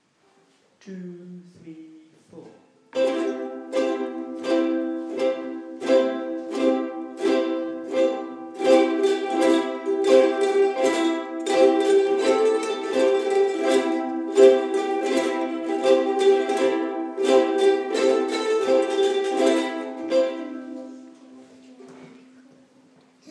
Apple tree no vocal